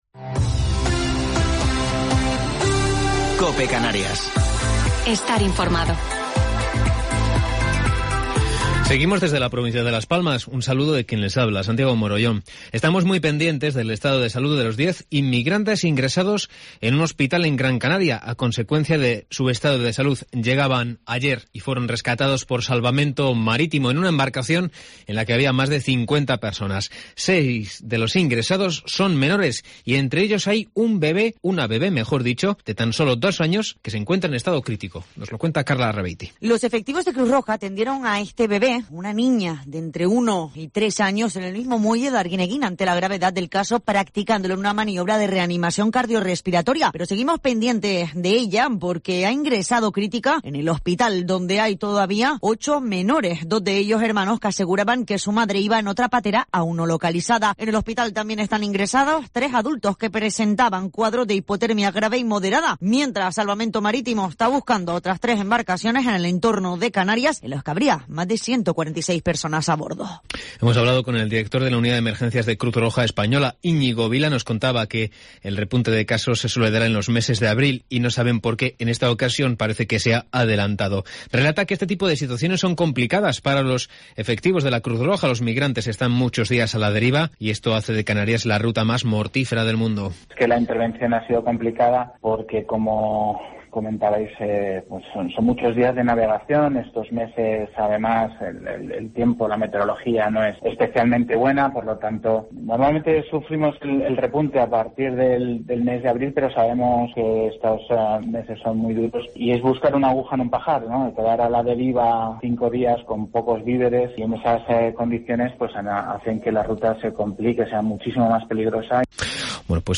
Informativo local 17 de Marzo del 2021